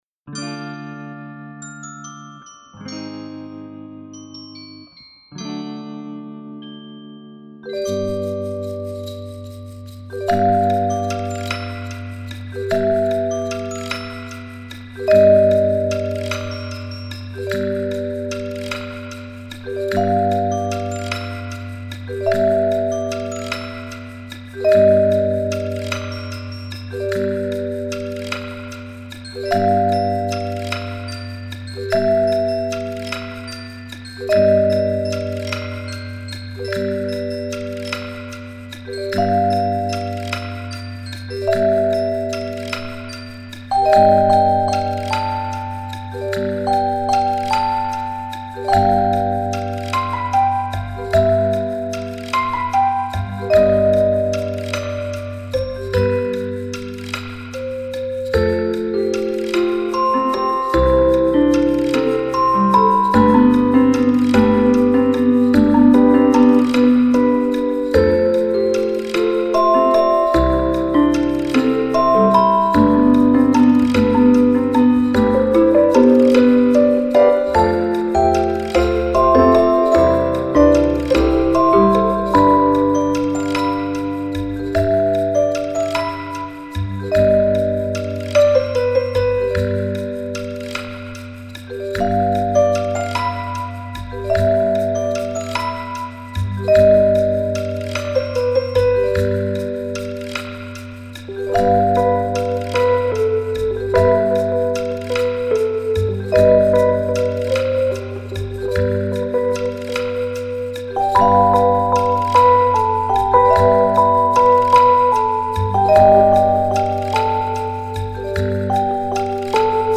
Genre: Instrumental, Music for Children